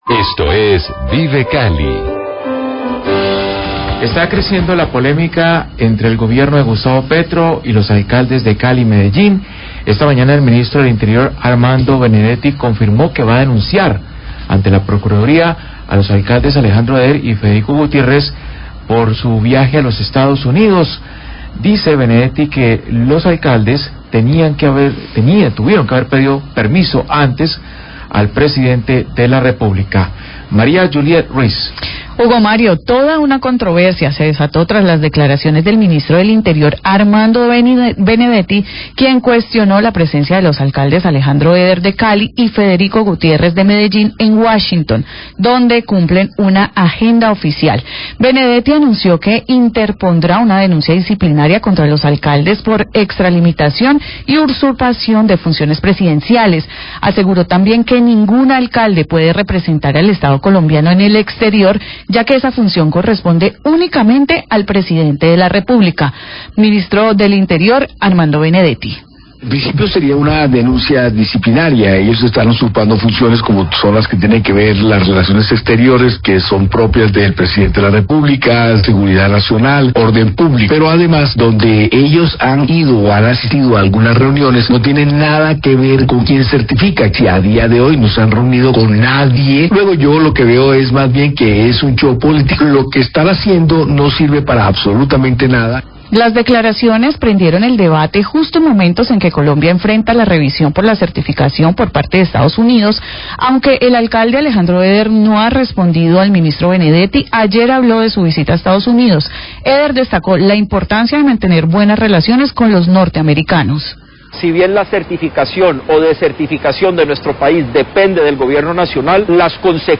Radio
Voces de los distintos entes institucionales se pronuncian sobre el viaje del alcalde Eder a los EEUU. Concejales hablan a favor y en contra.
Habla el alcalde Eder para explicar las razones de su viaje.